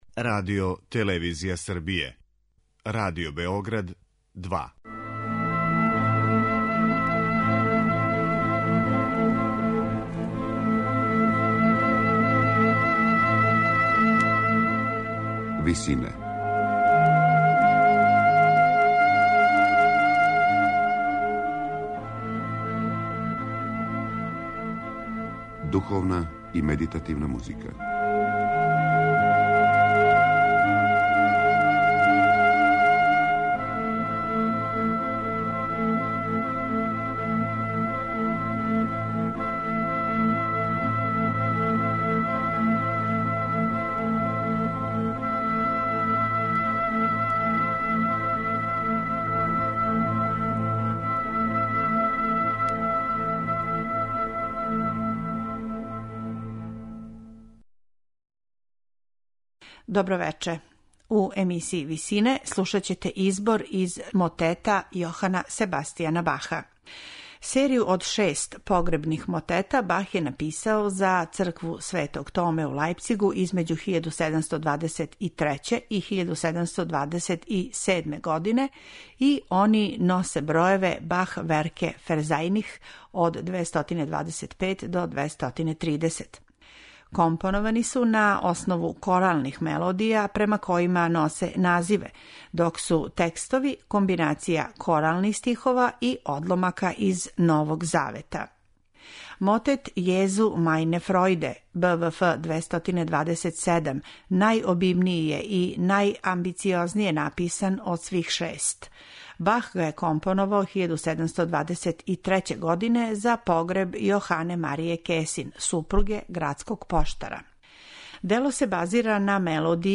Извођачи су камерни хор РИАС и ансамбл Академије за стару музику из Берлина. Диригује Ханс-Кристоф Радеман.